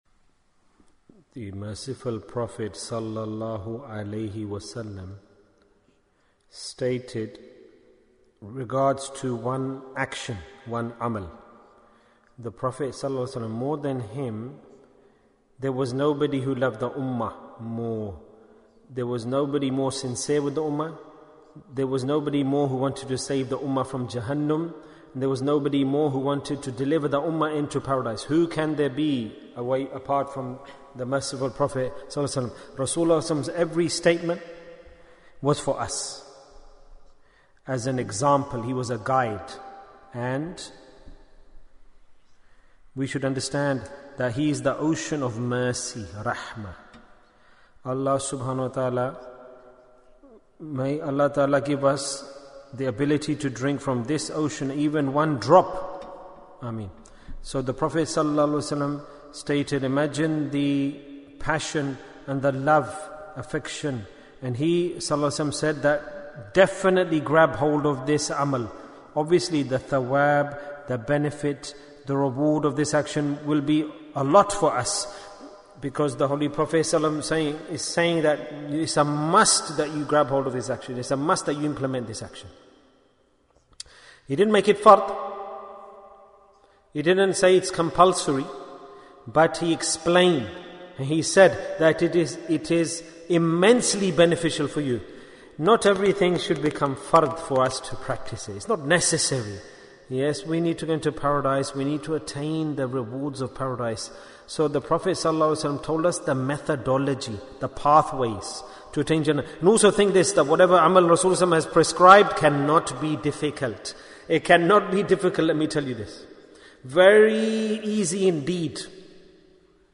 Heaps of Virtues Bayan, 27 minutes24th June, 2021